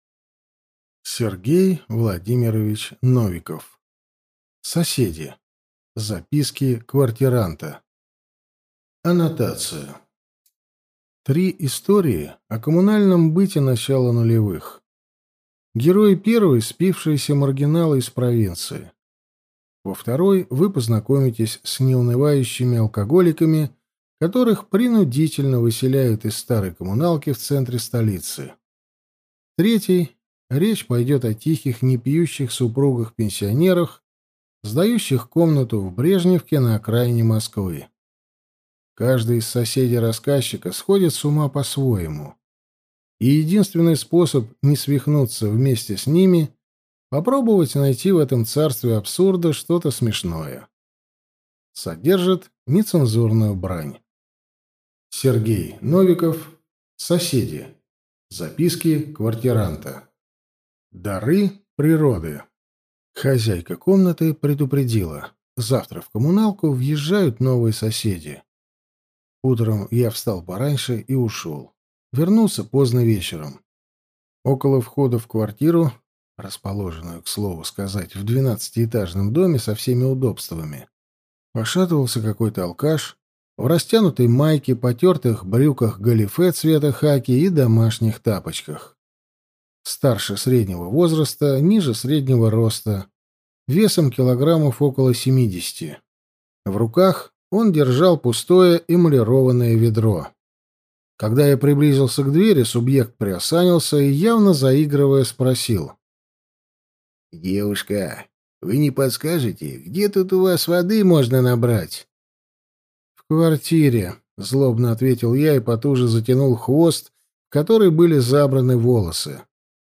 Аудиокнига Соседи. Записки квартиранта | Библиотека аудиокниг